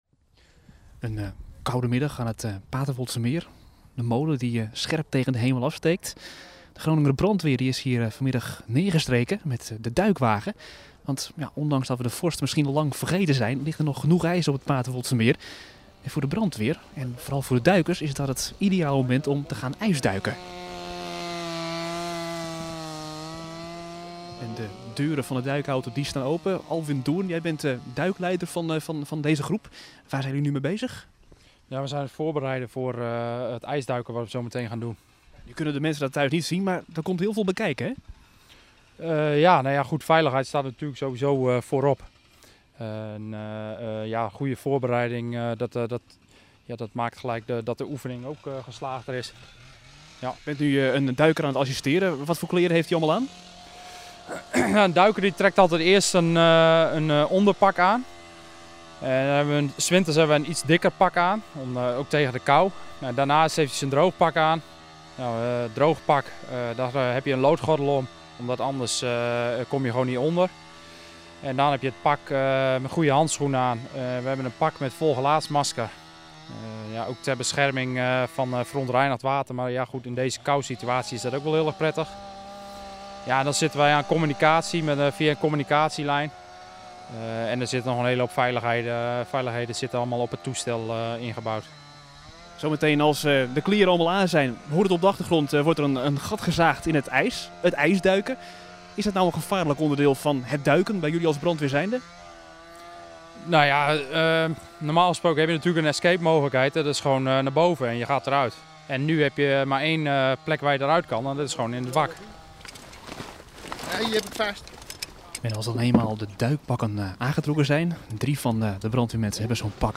Duikers van de Groninger brandweer oefenden donderdagmiddag in het zogenaamde ijsduiken. Dit gebeurde nabij de molen aan het Paterswoldsemeer.
reportage